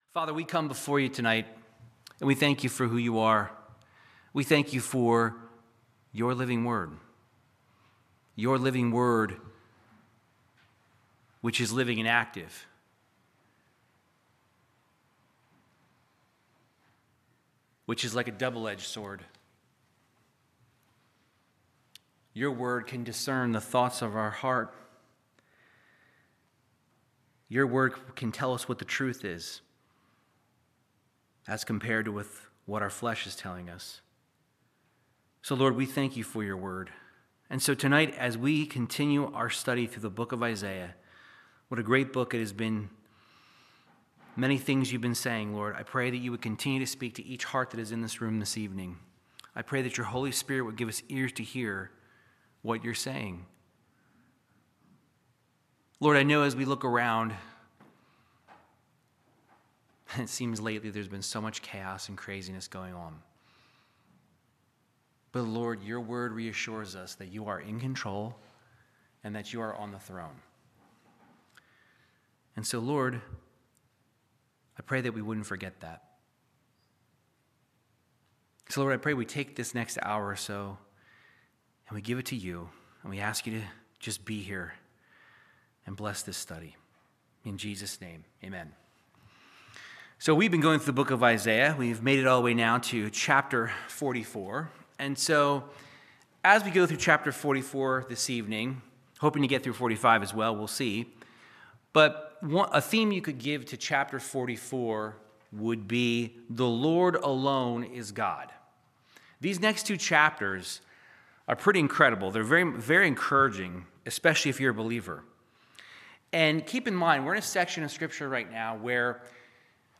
Verse by verse Bible teaching through the book of Isaiah chapters 44 and 45